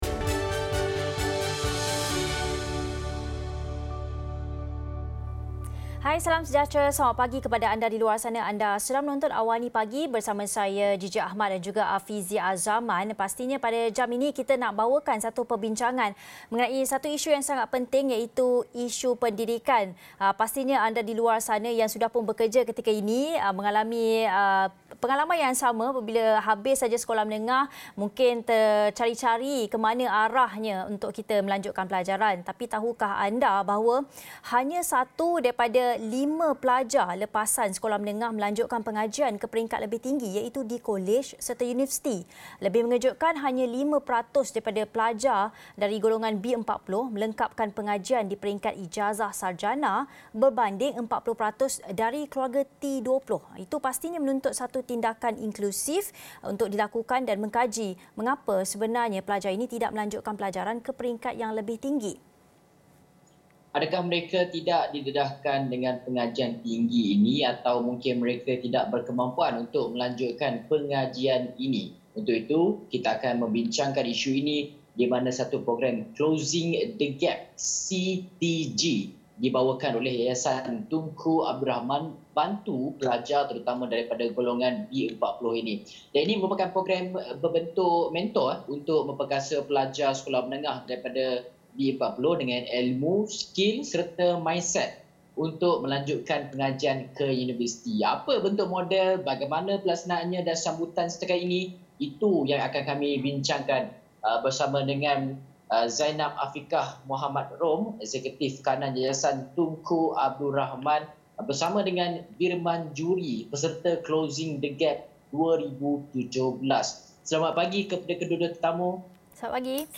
Saksikan perbincangan tentang program Closing The Gap (CTG) dibawakan oleh Yayasan Tunku Abdul Rahman, inisiatif berbentuk mentor untuk memperkasa pelajar sekolah menengah B40 dengan ilmu, skil serta mindset untuk melanjutkan pengajian ke universiti.